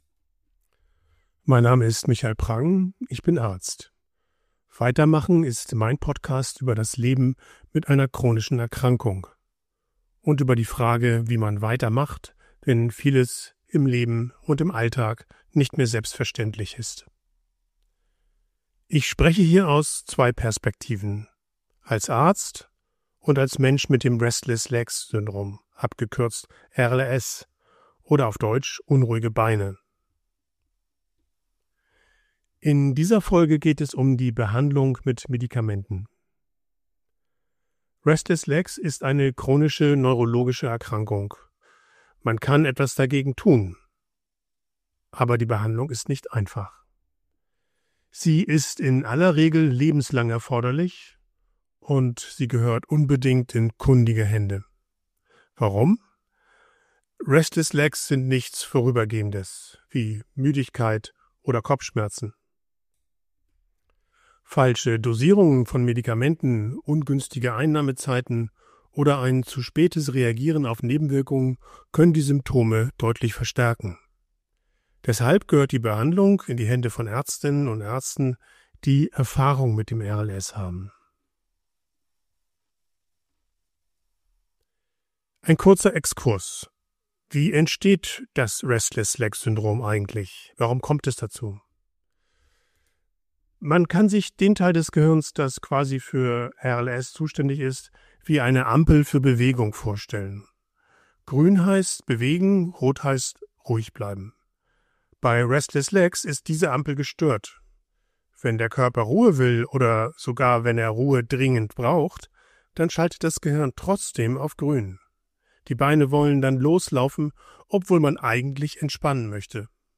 Ich spreche als Arzt und Betroffener über Alltag, Belastung und